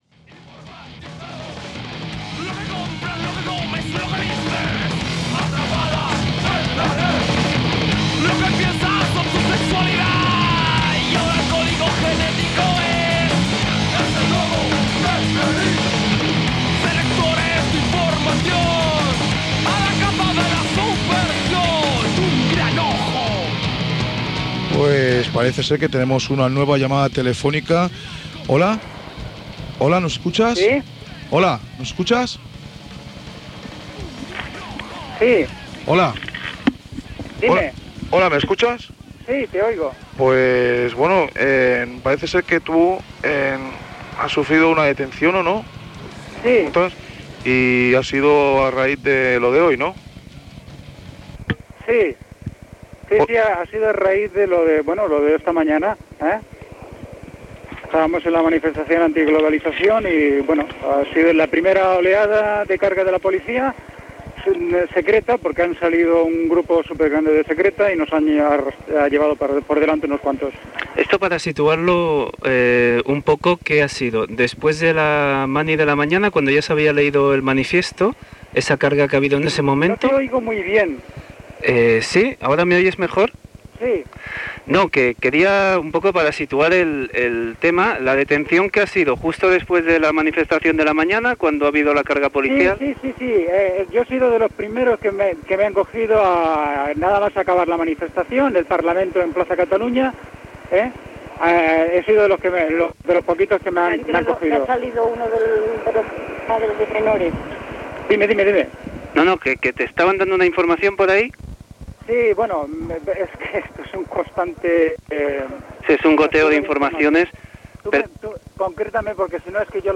Gènere radiofònic Informatiu Data emissió 2001-06-24 Banda FM Localitat Barcelona Comarca Barcelonès Durada enregistrament 13:50 Idioma Castellà Notes A Barcelona es van fer manifestacions en contra de la conferència que havia de fer el 25 de juny el Banc Mundial a Barcelona en contra seva del Fons Monetari Internacional.